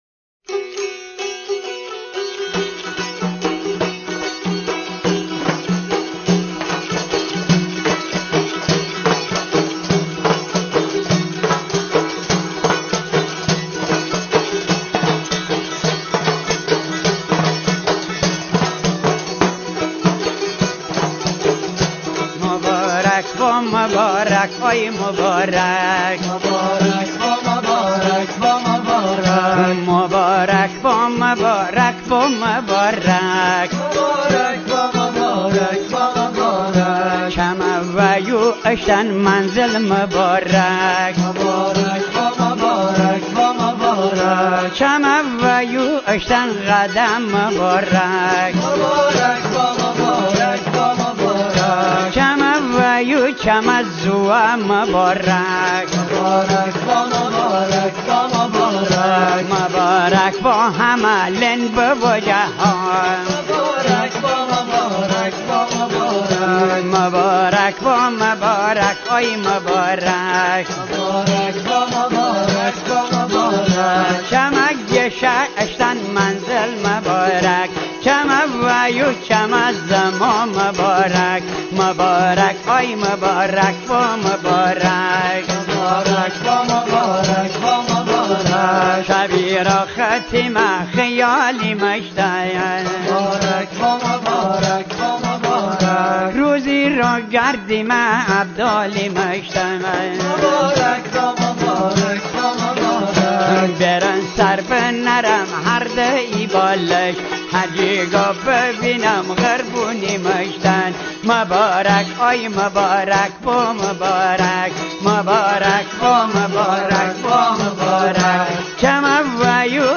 شادیانه‌های تالشی + صوت
نغمه‌های بزمی هم در مراسم عروسی و شادمانی‌ها در قالب ترانه‌هایی مانند «هیارهیار»، «مبارک با» و «آمان آمان» اجرا می‌شوند.
ترانه‌های تالشی که بسیار متنوع‌اند آذین‌بخش این مراسم هستند و سازهای سورنا، تمبوره و دایره، سازهای اصیل تالشی مجریان این نواهای شوق‌انگیزند.